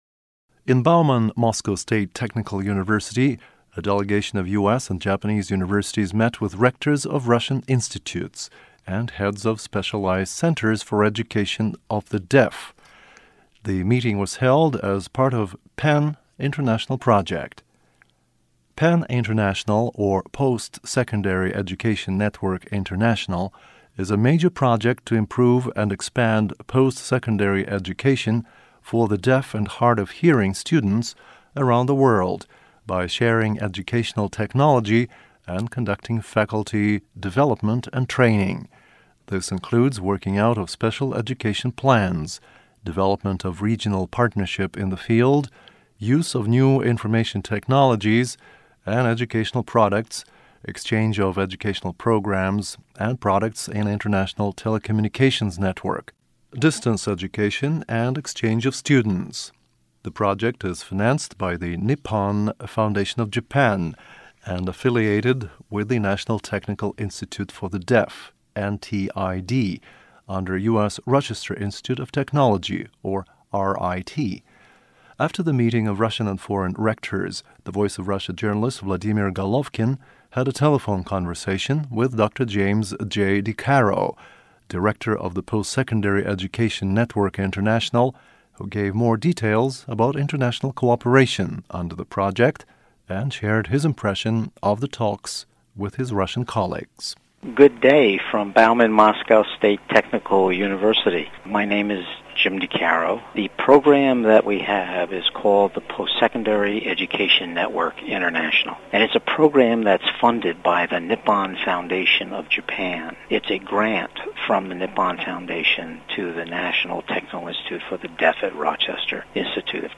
Radio Recording